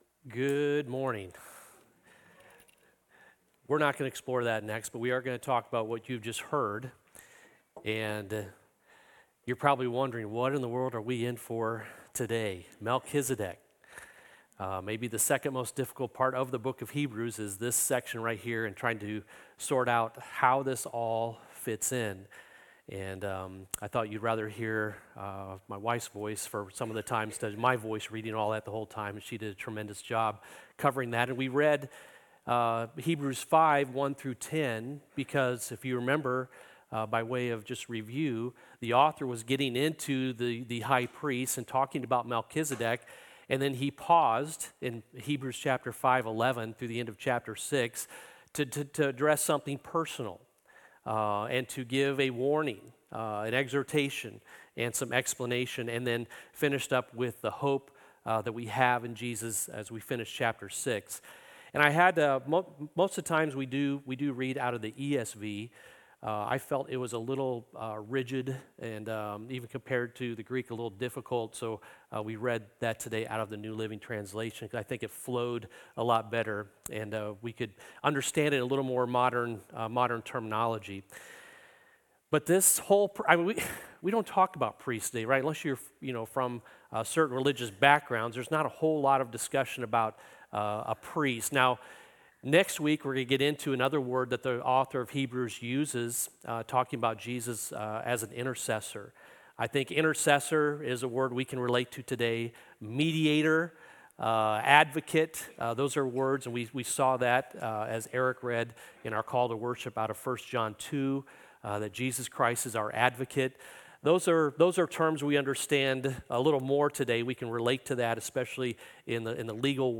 The sermon, part of the Jesus Is Better series on Hebrews, focuses on the eternal priesthood of Jesus Christ and how it offers believers a "better hope." It explores Hebrews 5:1-10 and 7:1-19, emphasizing Jesus' superiority over the Levitical priesthood through His divine appointment as High Priest in the order of Melchizedek.